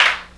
• Prominent Snare Drum E Key 06.wav
Royality free snare drum sound tuned to the E note. Loudest frequency: 2304Hz
prominent-snare-drum-e-key-06-M3a.wav